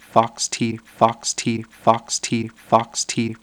Source: Fast body percussion (2:41-3:27)
Processing: stereo + KS = 90/75, F=970, input = 90, 0, 90 with rhythm, then constant, then KS = 37/32